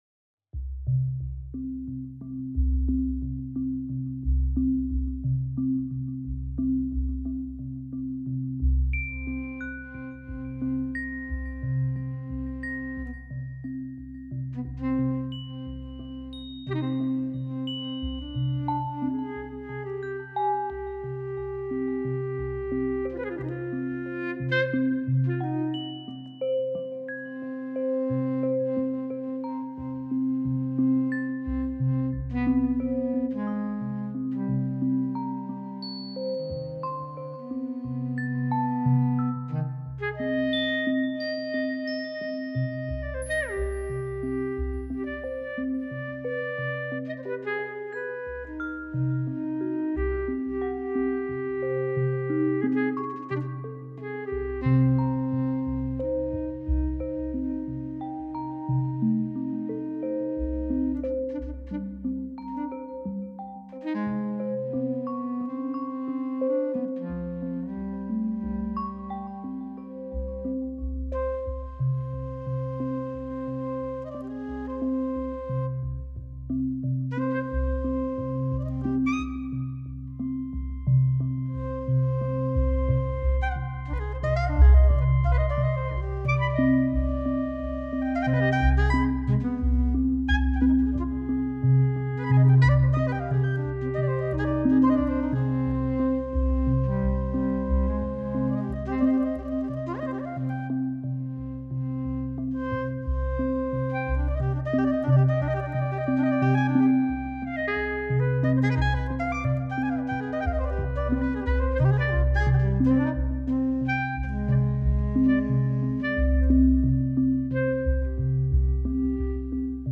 Composition pour :
Une clarinette,  Un sax soprano, Une séquence ornementée.
Superbe mélodie de saxophone…